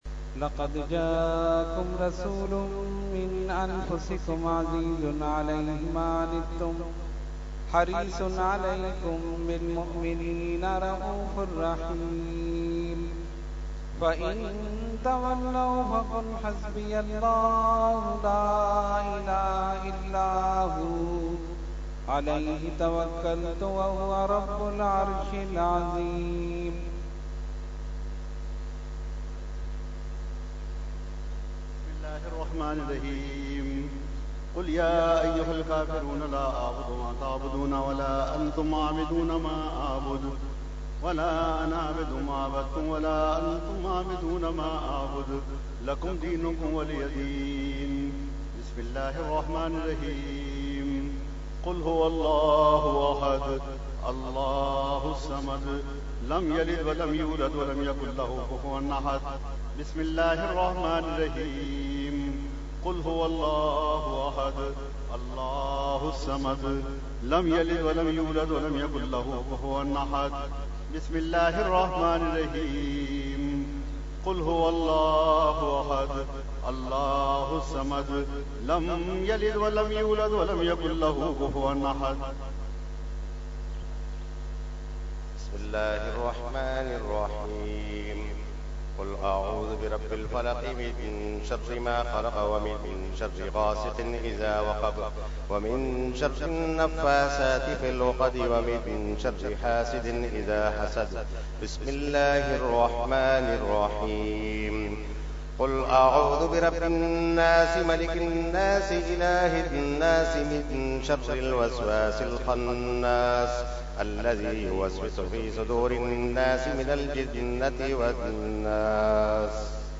Fatiha Shijrah – Urs Qutbe Rabbani 2017 Day 2 – Dargah Alia Ashrafia Karachi Pakistan
01-Fatiha And Shijra Shareef.mp3